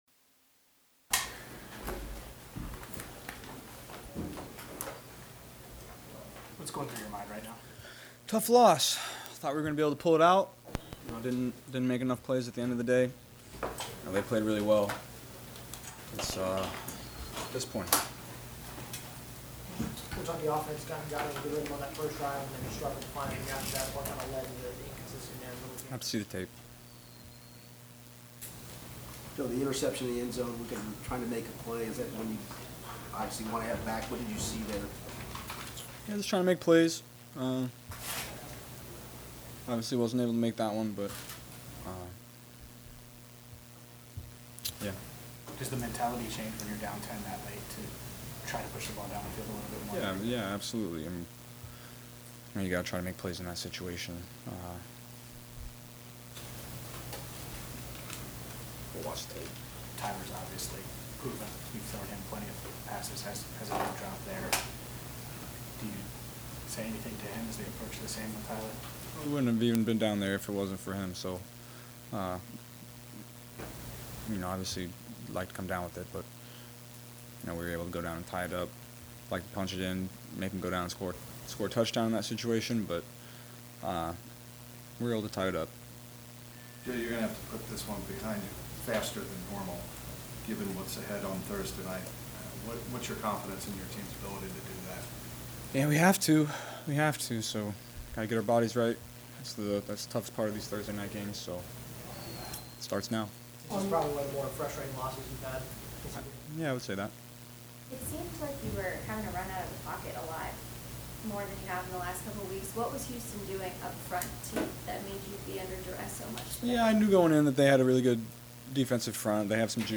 Bengals QB Joe Burrow talks with media after loss to Texans, 30-27